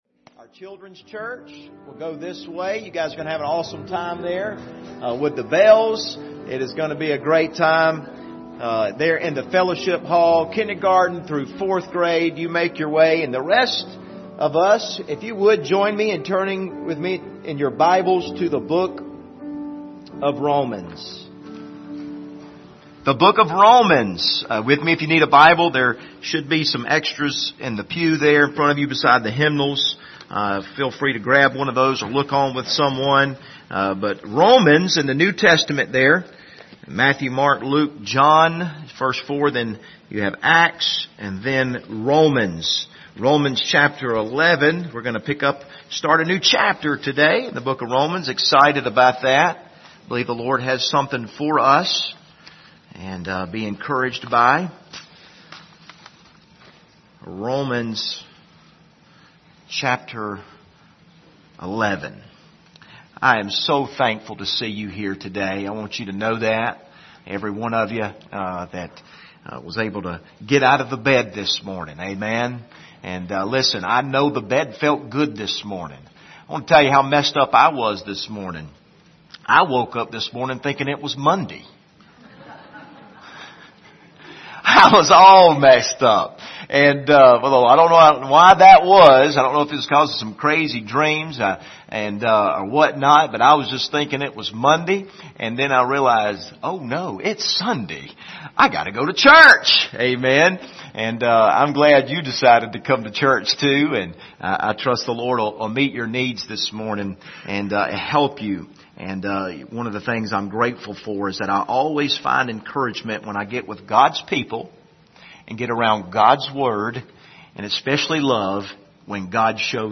Passage: Romans 11:1-8 Service Type: Sunday Morning